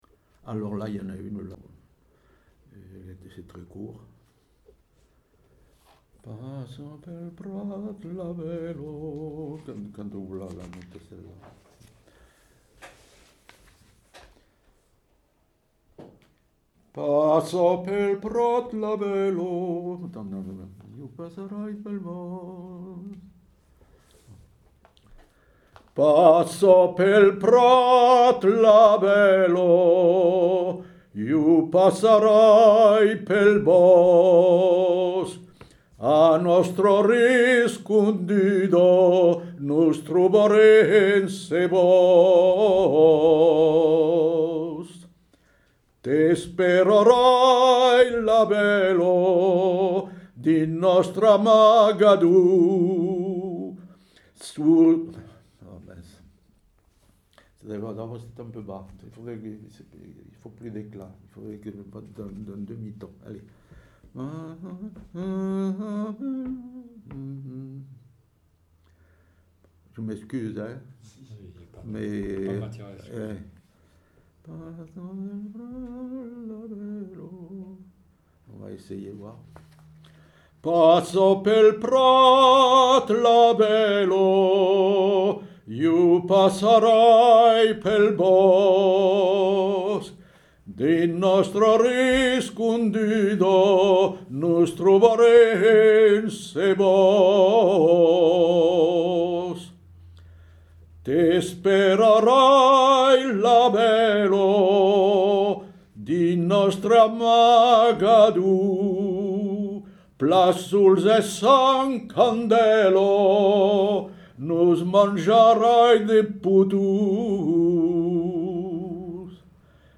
Aire culturelle : Rouergue
Lieu : Saint-Sauveur
Genre : chant
Effectif : 1
Type de voix : voix d'homme
Production du son : chanté
Notes consultables : L'interprète s'arrête au 2ème couplet et reprend du début.